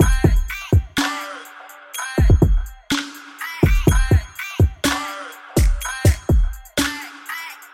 Tag: 124 bpm Hip Hop Loops Drum Loops 1.30 MB wav Key : Unknown